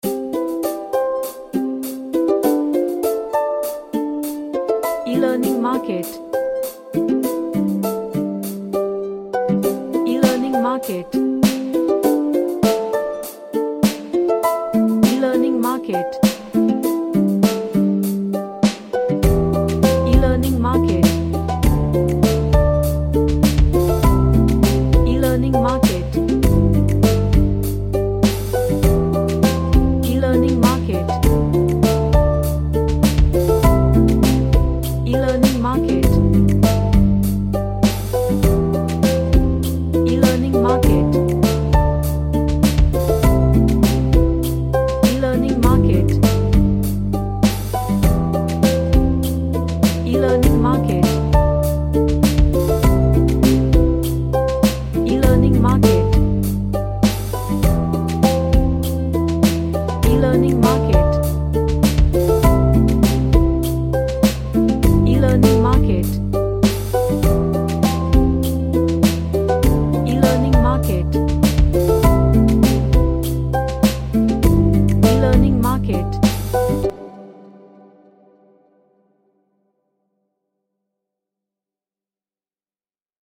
An Acoustic track with lots of strumming.
Happy